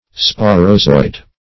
Sporozoite \Spo`ro*zo"ite\ (sp[=o]`r[-o]*z[=o]"[imac]t), n.